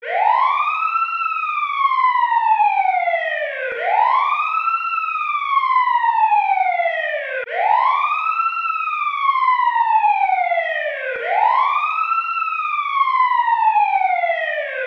siren2.ogg